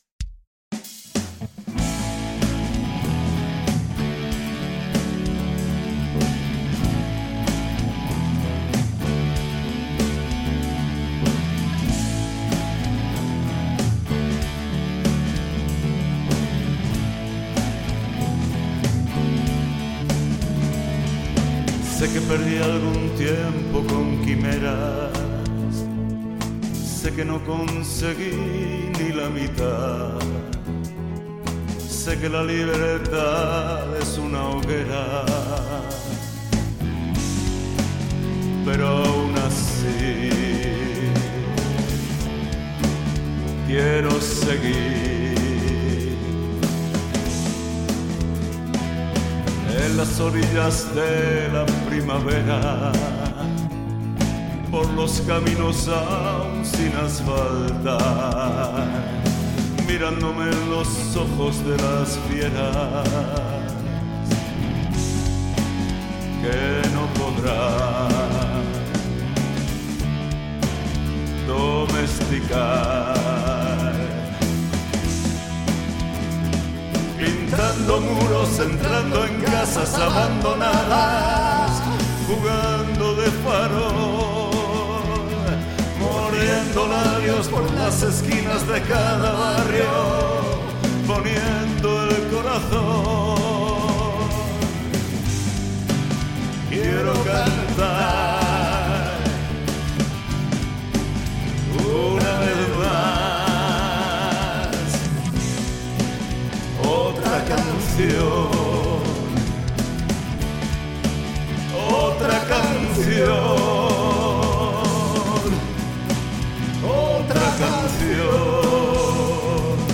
canciones de la música popular española del siglo XX
un repertorio tranquilo de canciones de amor